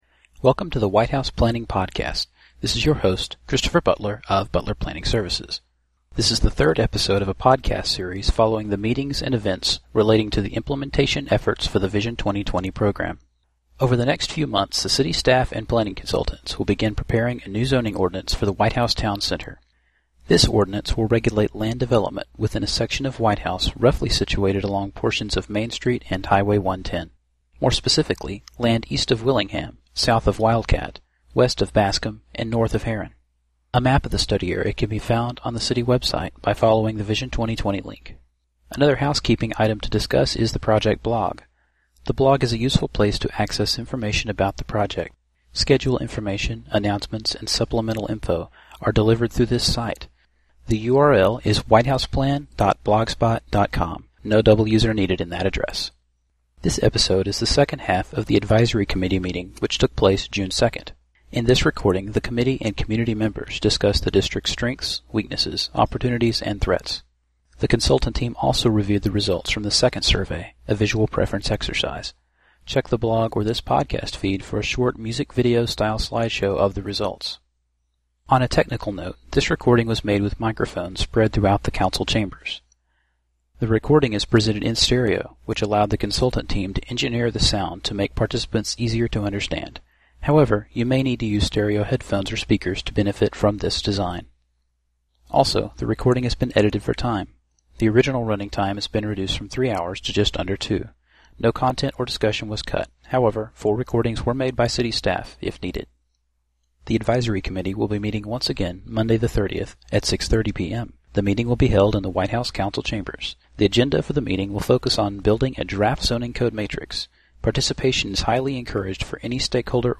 Anyone interested in hearing a recording of the June 2nd Advisory Committee Meeting can do so now.
Meeting recordings will typically be edited for length to reduce listening time requirements.